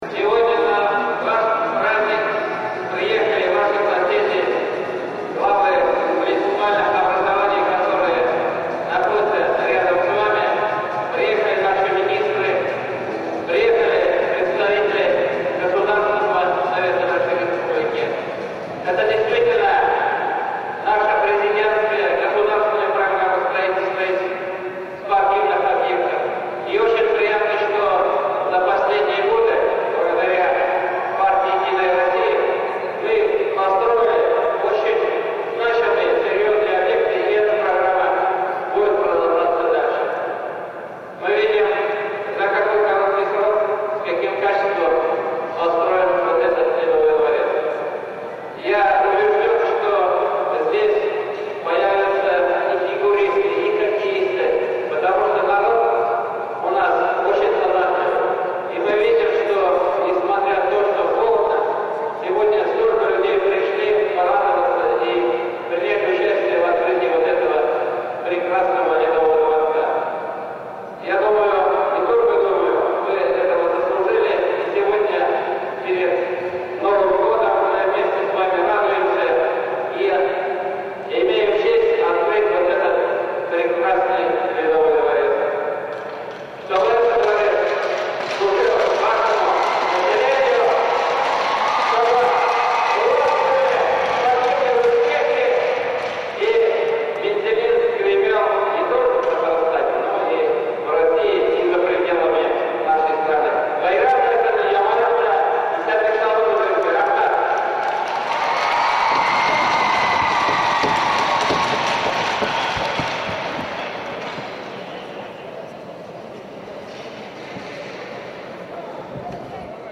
Торжественное открытие Ледового дворца в г.Мензелинске с участием Премьер-министра Республики Татарстан Р.Н.Минниханова
Выступление Премьер-министра Республики Татарстан Р.Н.Минниханова Ледового дворца в Мензелинске